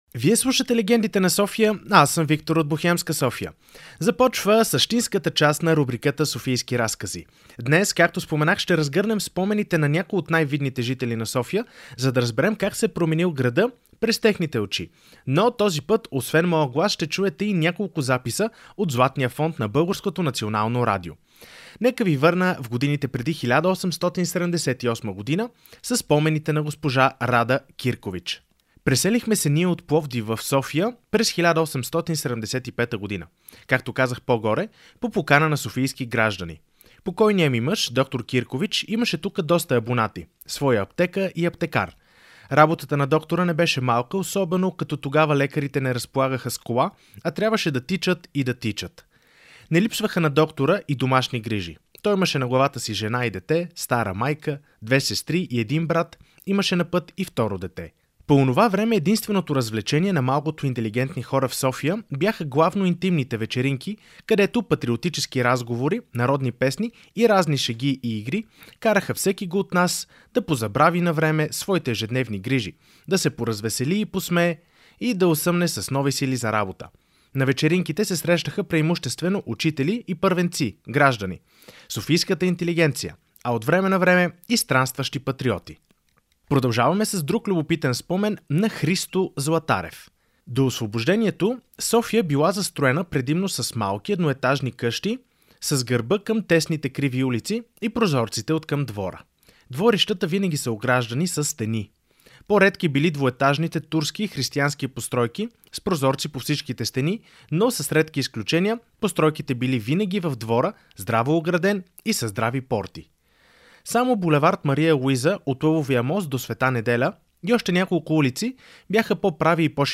С помощта на писмени свидетелства, а също и записи от Златния фонд на БНР се връщаме назад във времето.